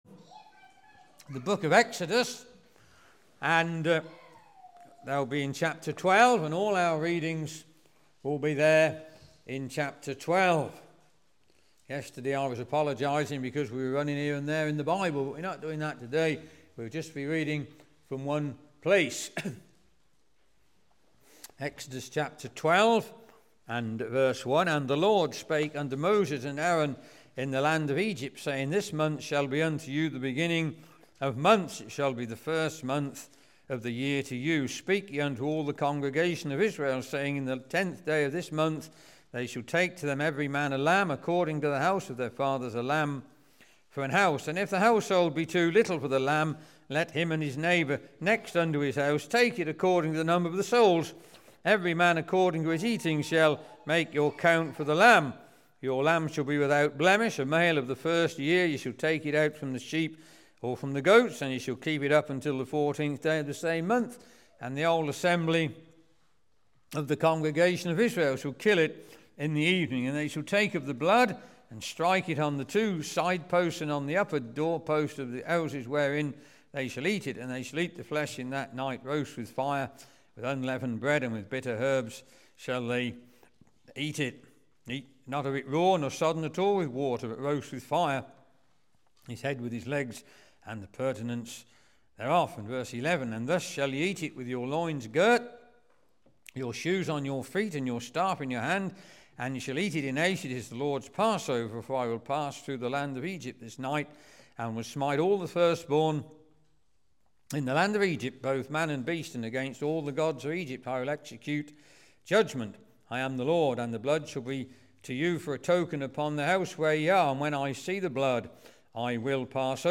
A stirring and clear gospel message for you from an Old Testament real life illustration.